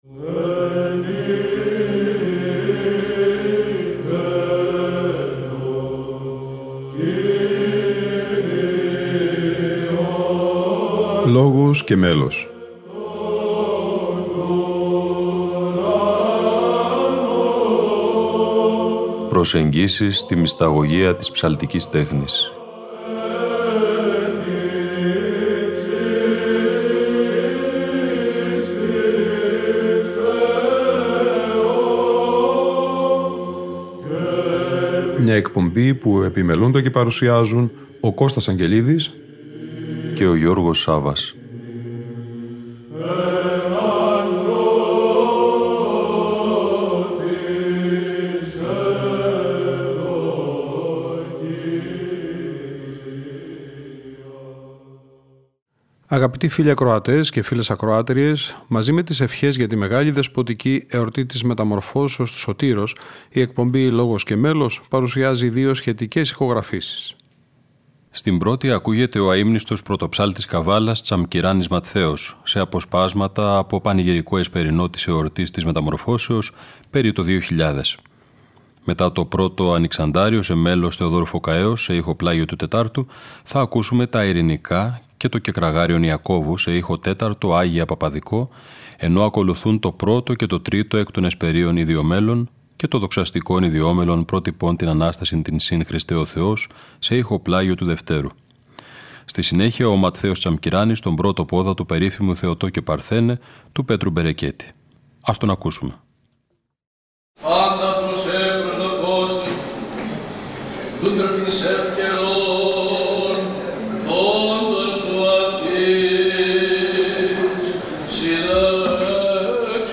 Εσπερινός Μεταμορφώσεως του Σωτήρος